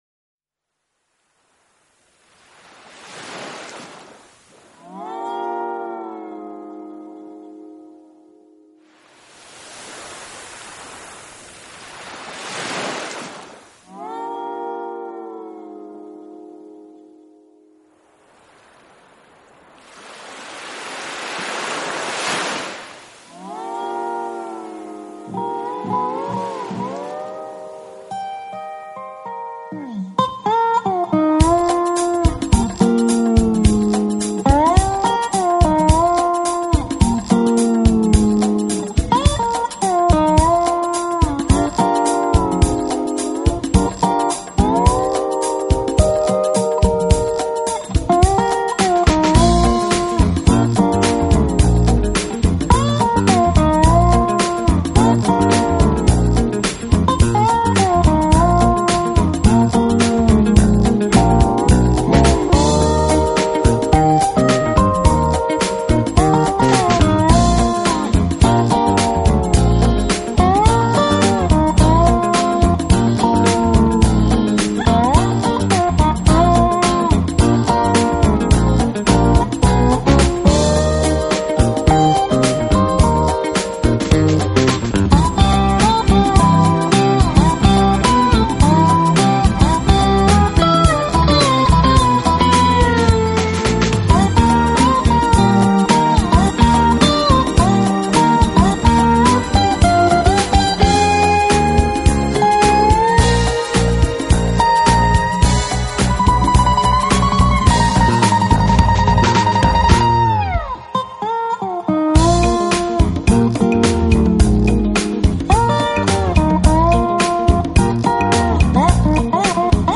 【Jazz & Fusion】
synthesizers